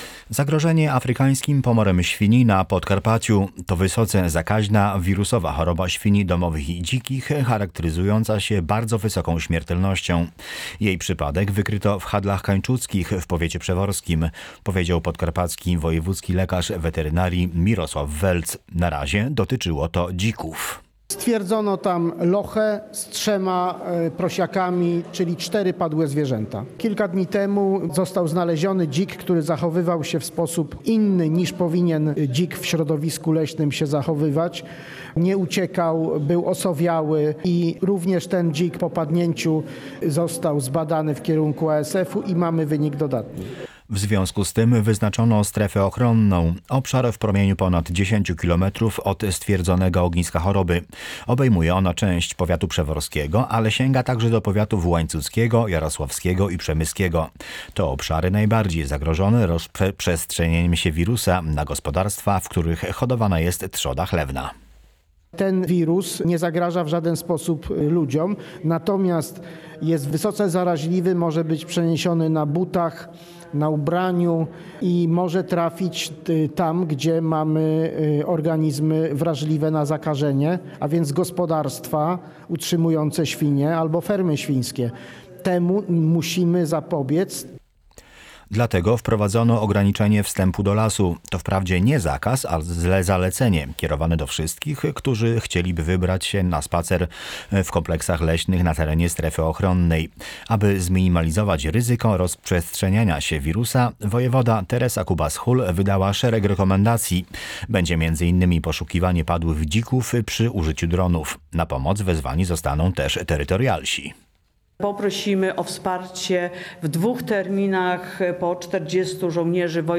Wyznaczono strefę ochronną po wykryciu ASF u dzików • Relacje reporterskie • Polskie Radio Rzeszów